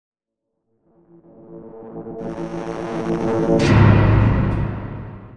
dronereturn.wav